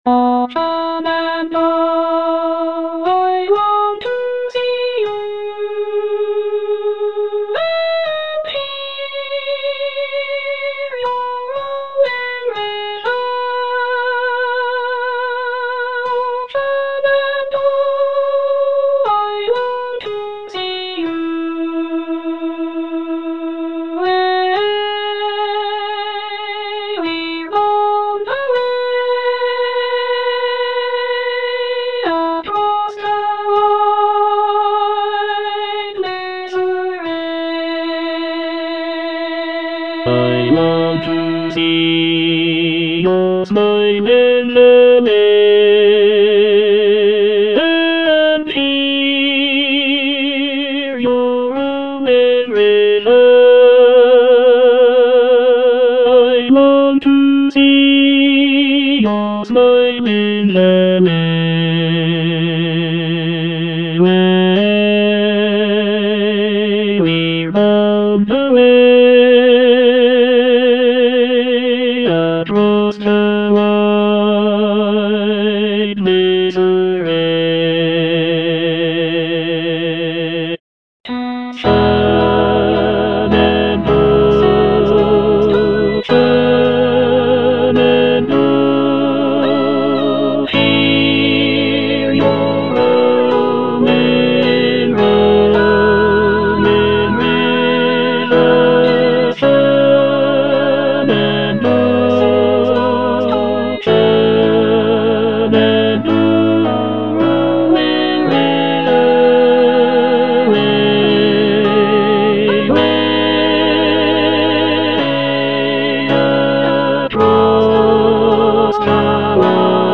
ANONYMOUS (ARR. J. ERB) - SHENANDOAH Tenor I (Emphasised voice and other voices) Ads stop: auto-stop